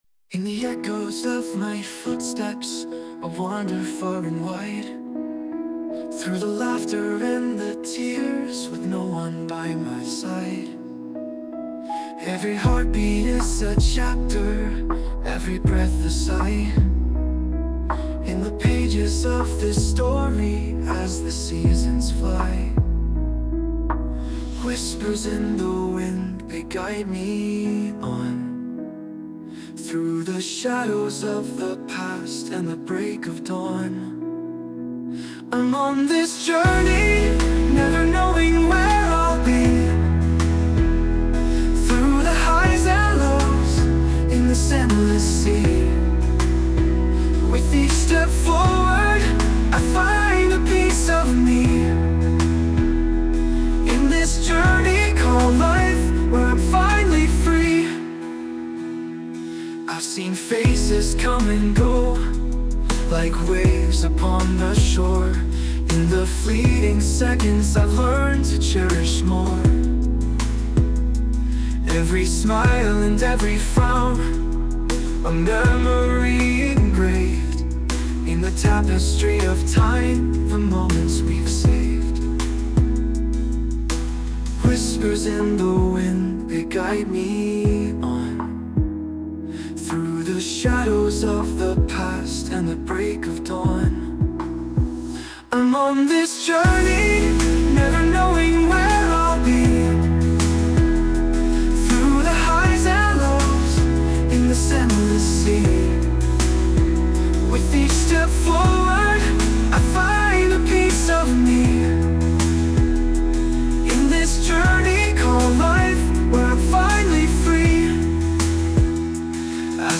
洋楽男性ボーカル著作権フリーBGM ボーカル
著作権フリーのオリジナルBGMです。
男性ボーカル（洋楽・英語）曲です。
人生を旅に例えた内容の歌詞に前向きになれるような曲調をつけて制作しました！